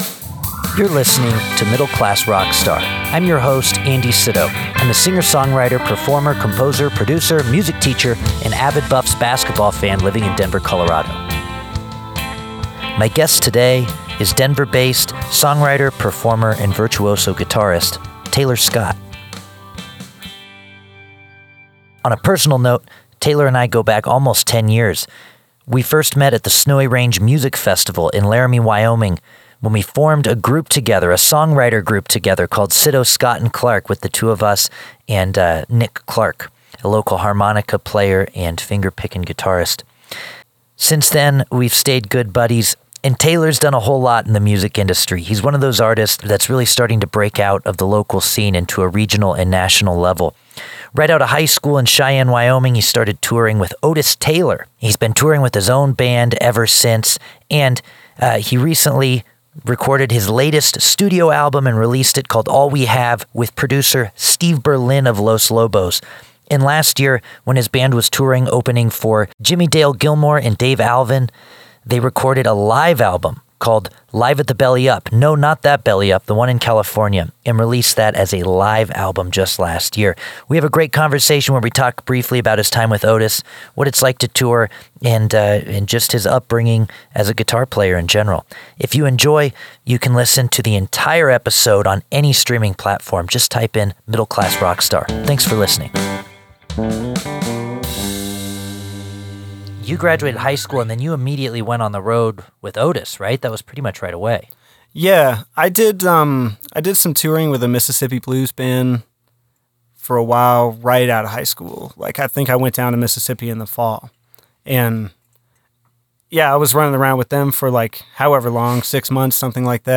Every week or so I feature a 7 to 8 minute segment featuring  a conversation